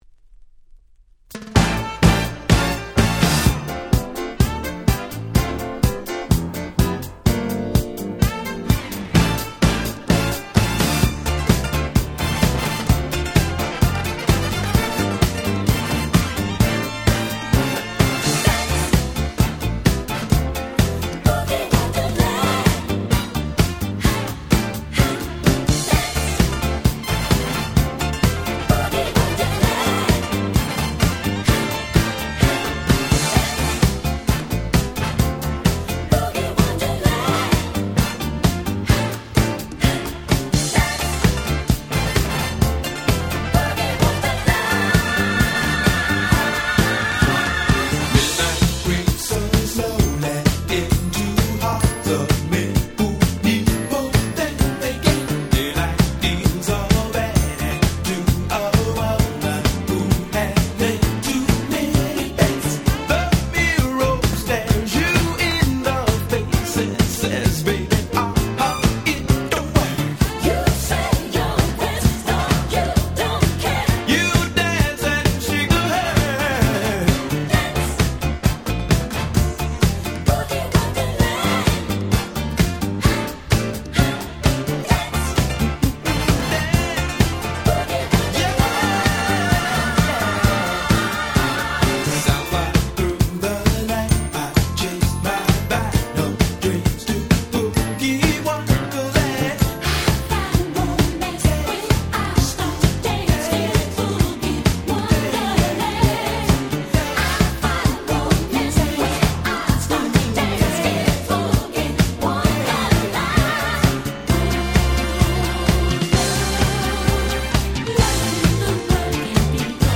音質もバッチリだし使い易いしで海外でも非常に人気で安定した価格のシリーズです！
ディスコ Disco ダンクラ ダンスクラシックス Dance Classics